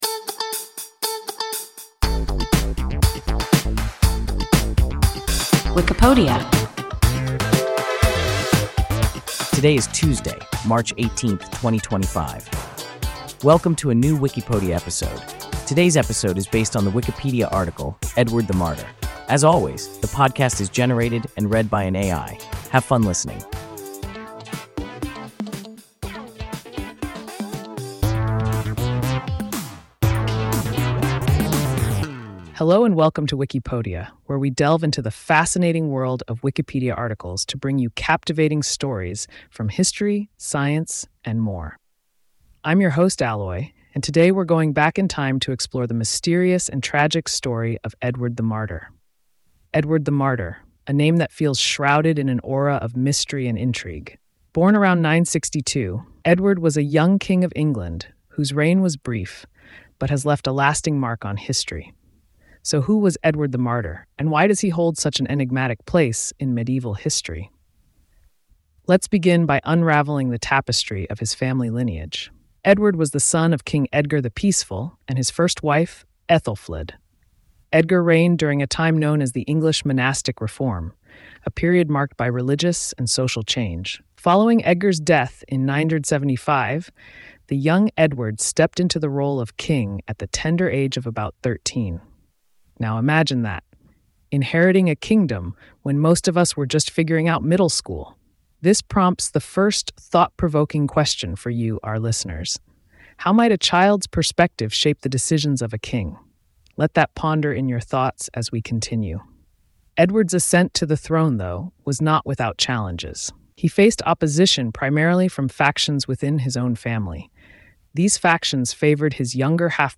Edward the Martyr – WIKIPODIA – ein KI Podcast